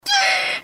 High Screech